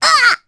Rephy-Vox_Damage_jp_03.wav